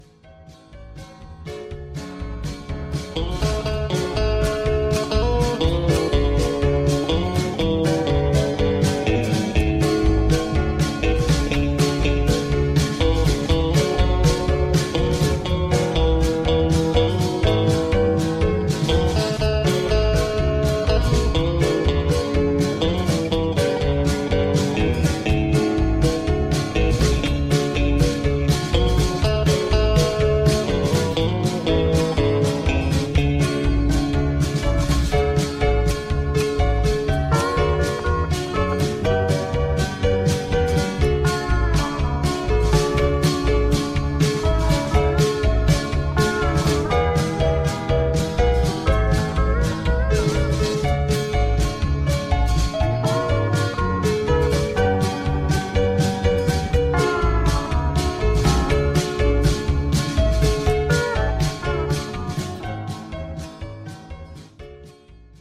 (Low Key)
Singing Calls
Gospel